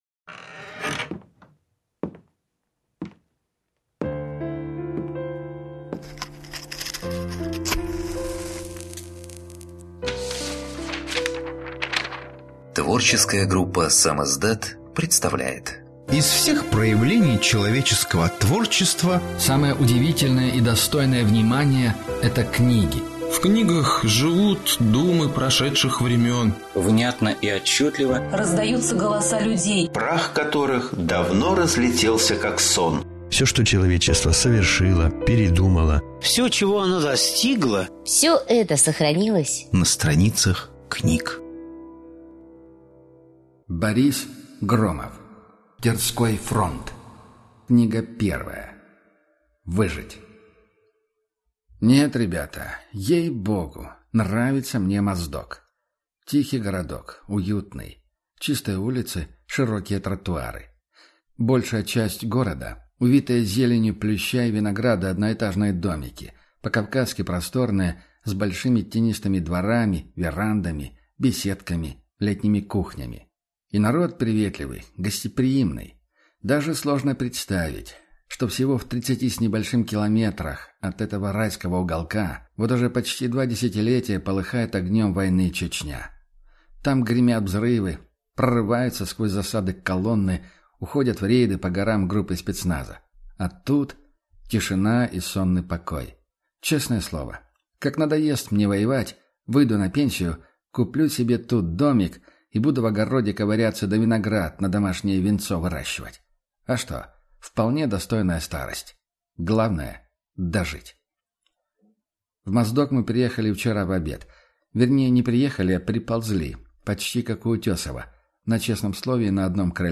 Аудиокнига Выжить | Библиотека аудиокниг